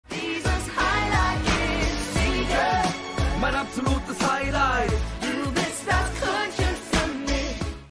vict---1220-kroenchensaenger-ausschnitt-lied-kurz.mp3